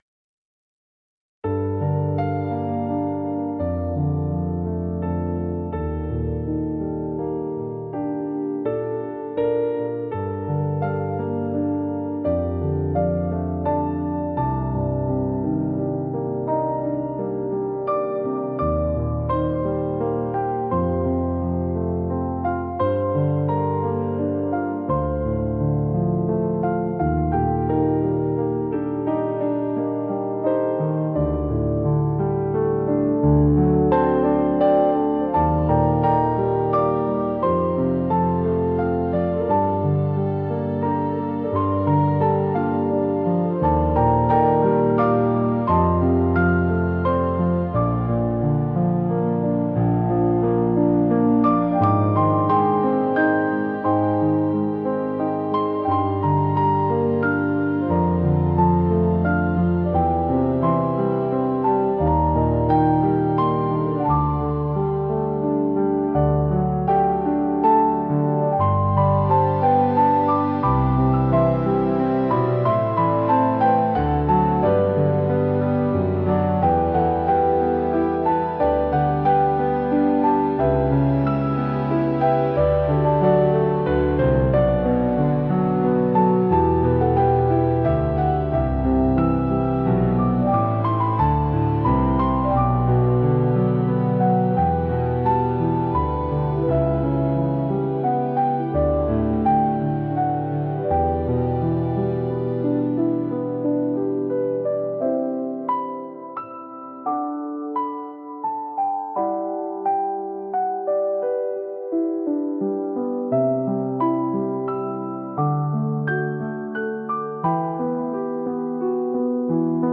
Genre: Orchestral Mood: Memories Editor's Choice